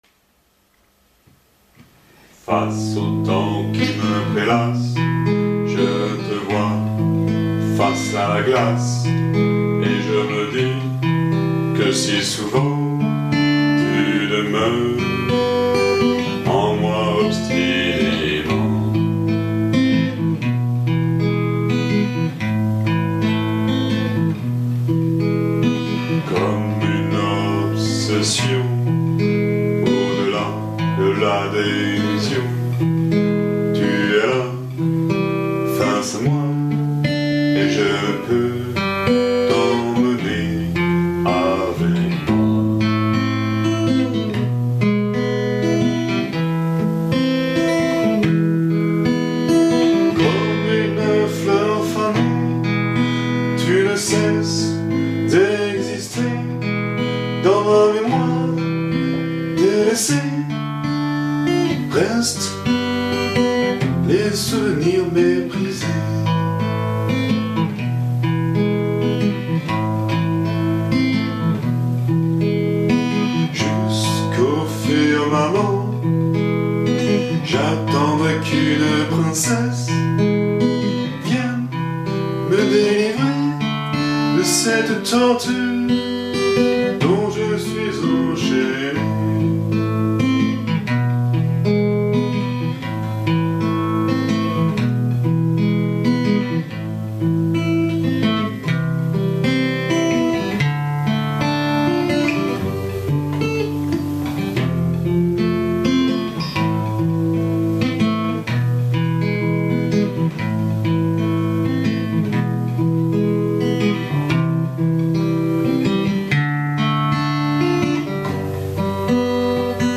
Composittion guitare + chant